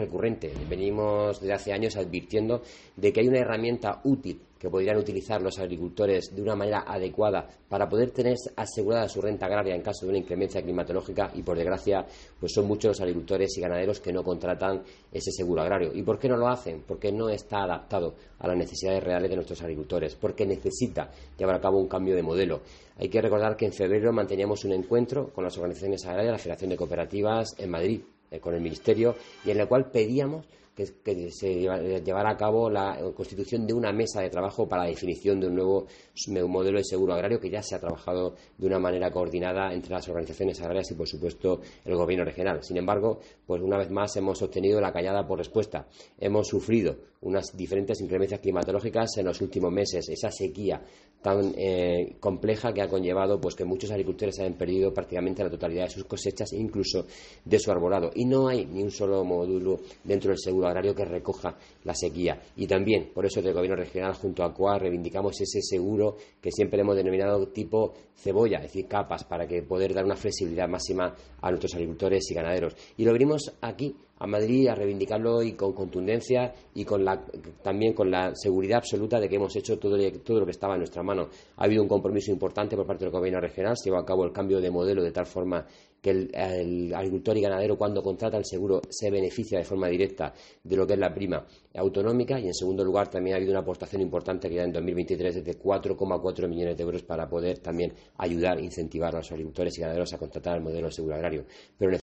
Antonio Luengo, consejero de Agricultura en funciones